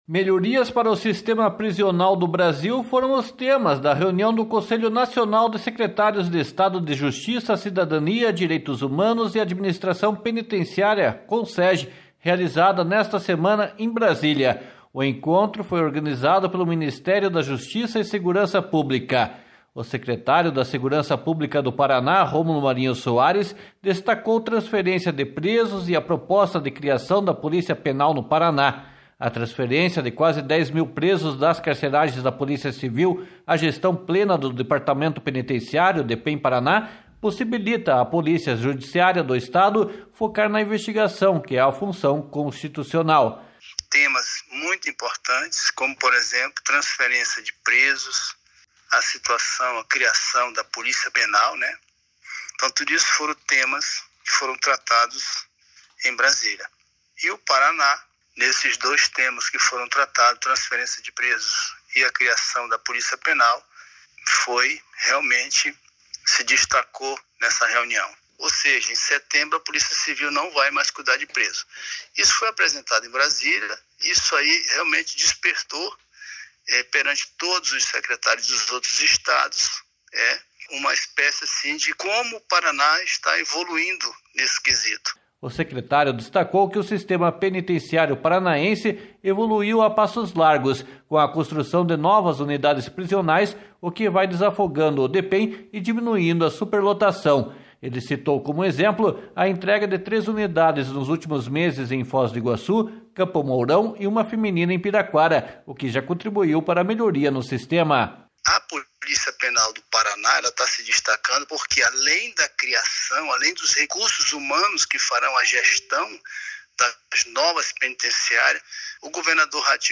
//SONORA ROMULO MARINHO SOARES//